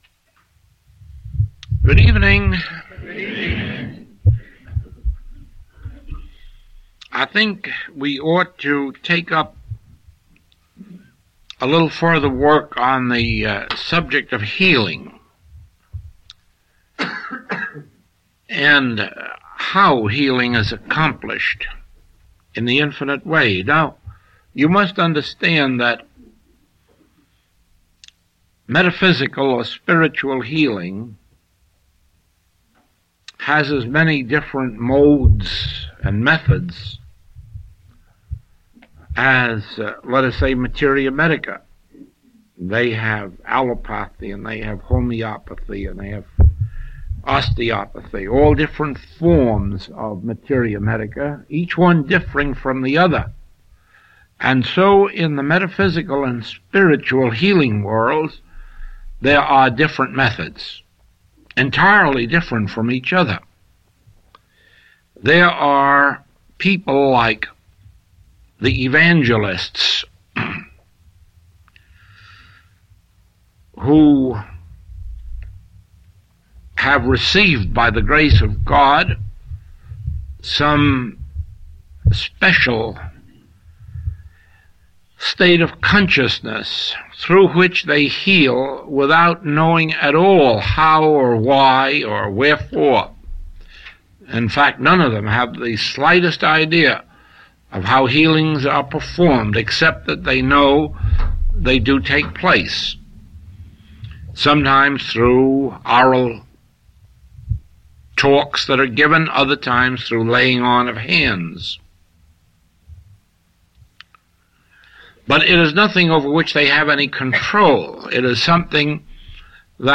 Recording 477B is from the 1962 Princess Kaiulani Open Class.